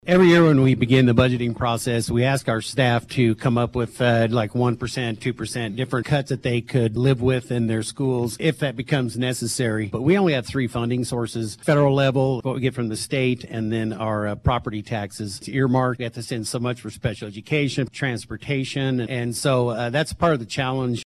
Rising property taxes was a point of emphasis at the USD 383 candidate forum, hosted by the League of Women Voters Saturday.